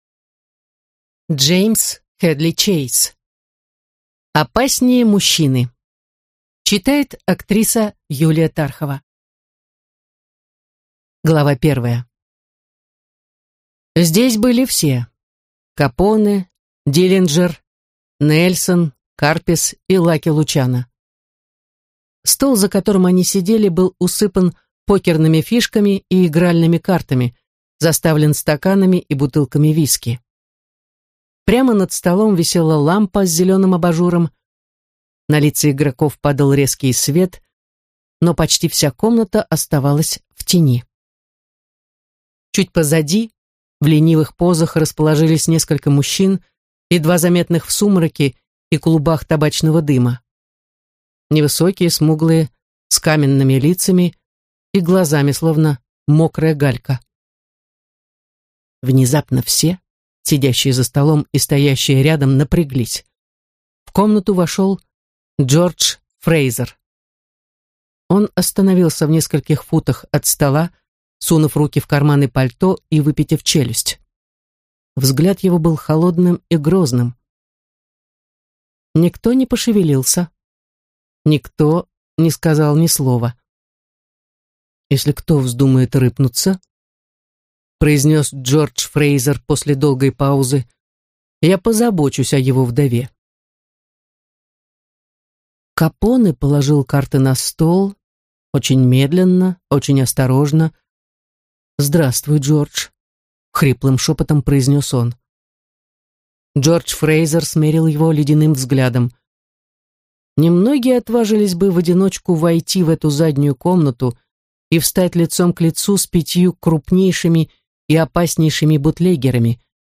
Аудиокнига Опаснее мужчины | Библиотека аудиокниг